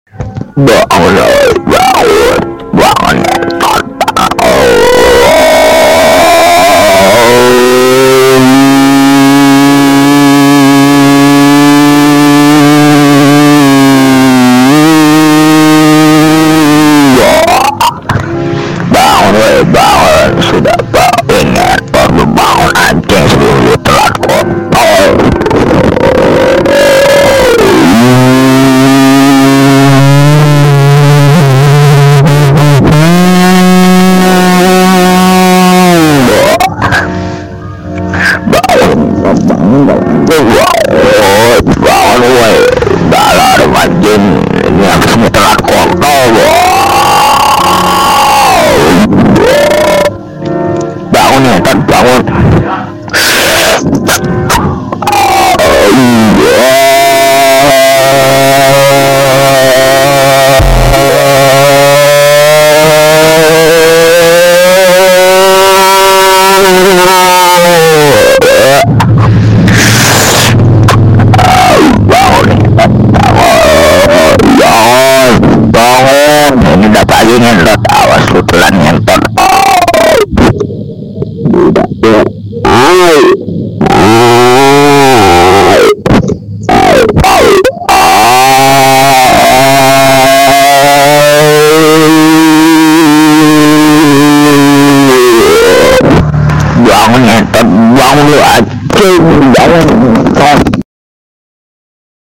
Nada dering alarm Berisik
Keterangan: Download nada dering alarm berisik untuk hp Anda.
nada-dering-alarm-berisik-id-www_tiengdong_com.mp3